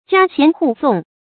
家弦戶誦 注音： ㄐㄧㄚ ㄒㄧㄢˊ ㄏㄨˋ ㄙㄨㄙˋ 讀音讀法： 意思解釋： 弦：弦歌；用琴瑟伴奏來吟誦。